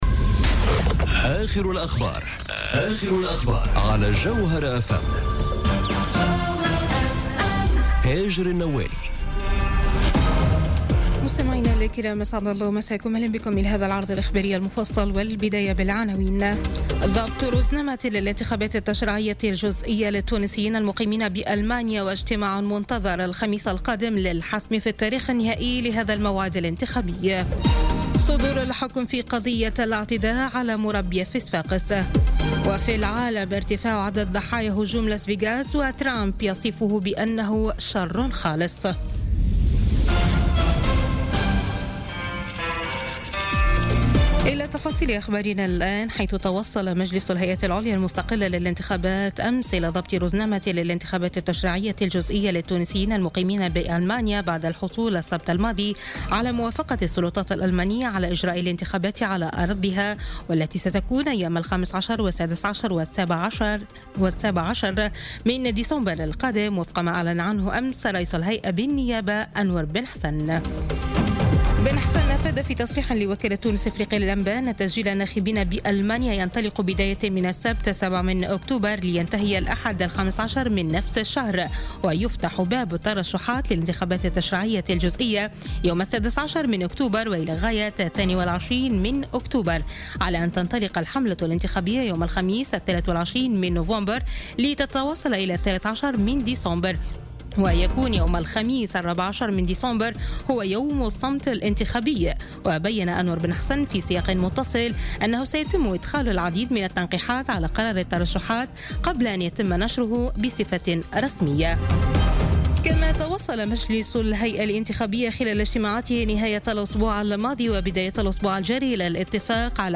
نشرة أخبار منتصف الليل ليوم الثلاثاء 3 أكتوبر 2017